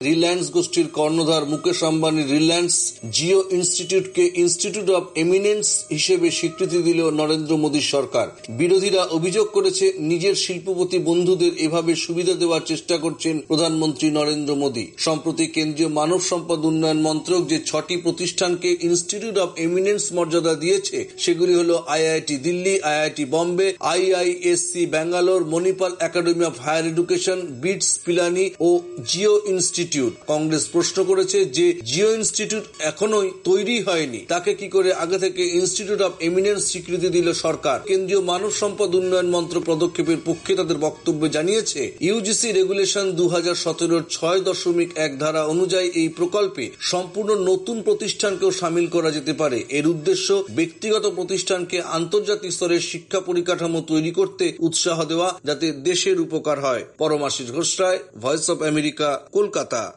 কলকাতা সংবাদদাতা